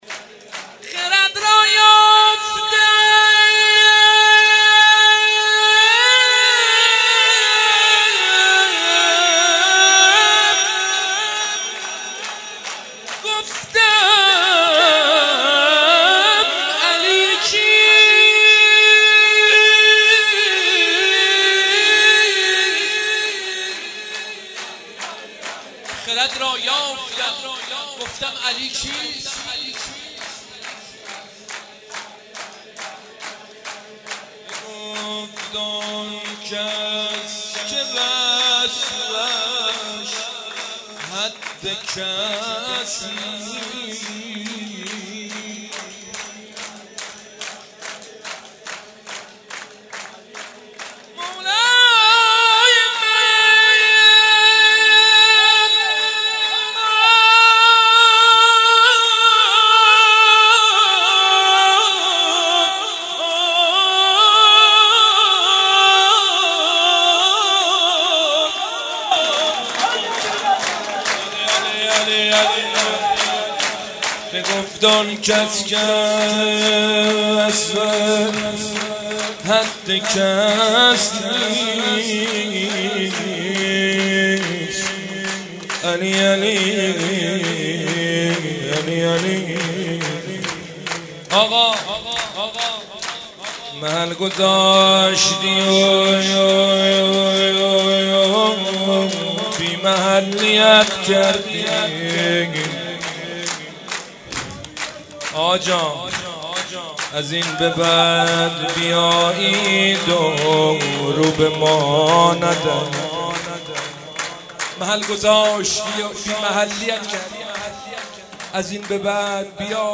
جشن نیمه شعبان/هیت روضه العباس(ع)